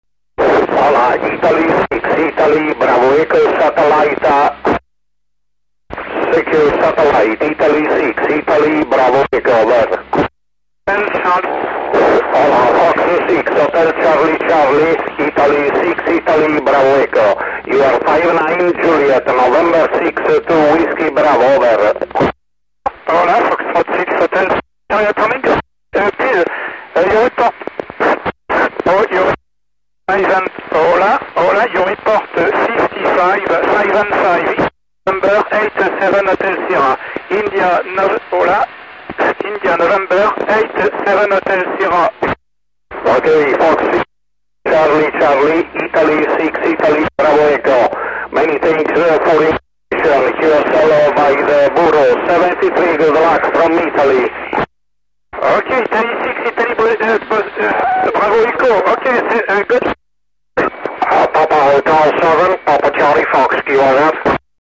modo operativo VOCE - uplink 437.800 Mhz FM , downlink 145.800 Mhz FM + - Doppler